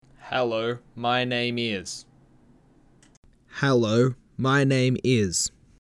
Recorded some talking. 1st line is no box. 2nd line with box. Some distants apart, same mic. Rode M3 Sorry it add to be Mp3, it would not let me upload Wave Attachments Box Test.mp3 Box Test.mp3 229.6 KB · Views: 100